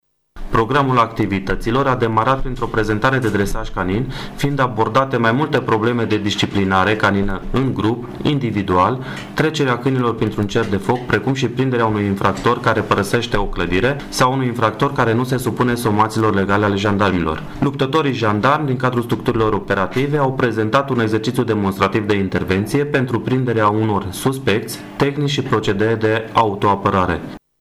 Gruparea de Jandarmi Mobilă Tîrgu Mureş a fost, astăzi, gazdă pentru câteva sute de copii de la două şcoli gimnaziale şi două grădiniţe din Tîrgu-Mureş în cadrul programului „Şcoala Altfel”.